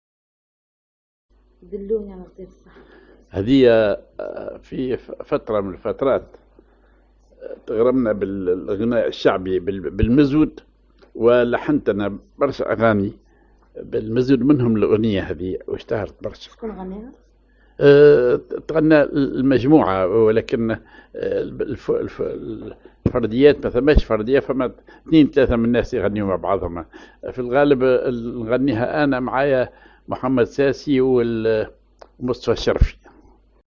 genre أغنية